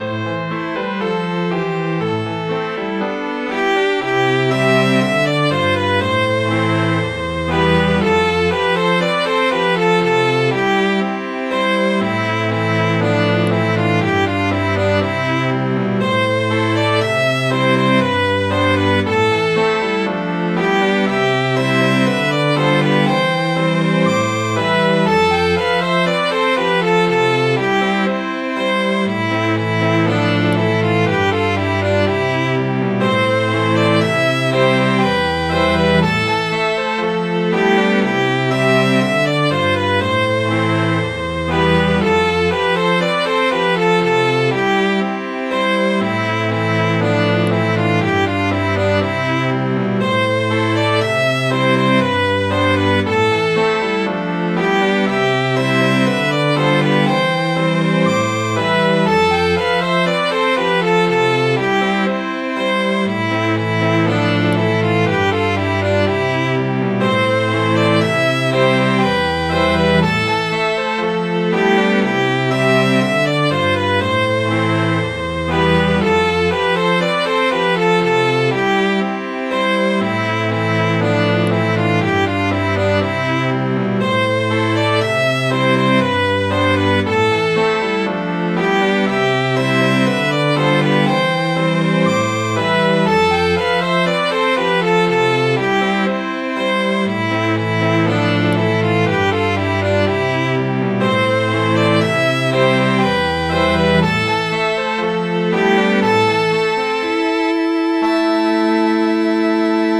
Midi File, Lyrics and Information to It's of a Sailor Bold